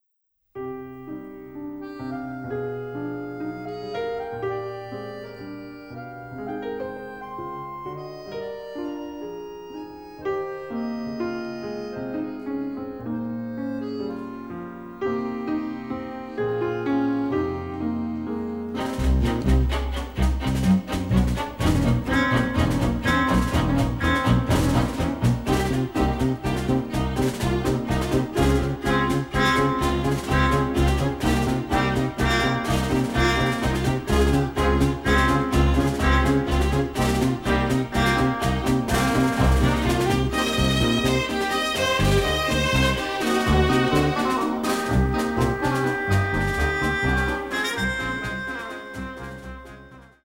Recorded in London
short dynamic and rythmical score